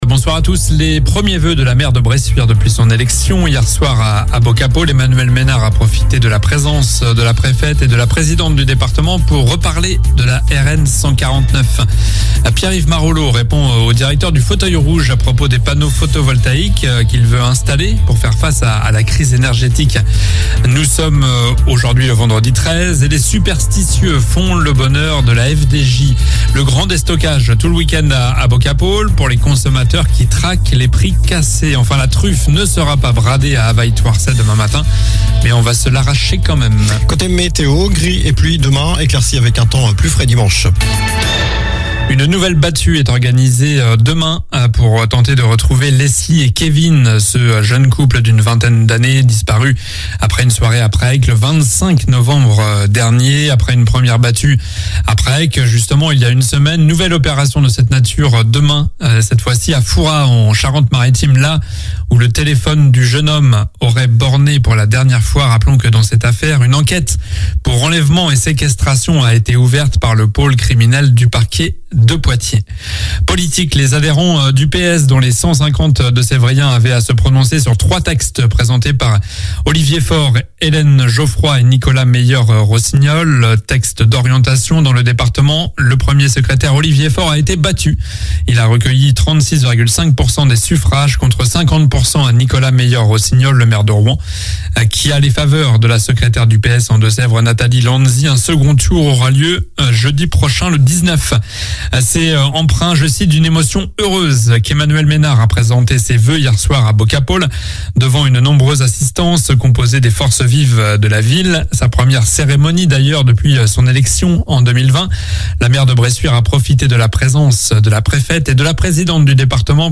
Journal du vendredi 13 janvier (soir)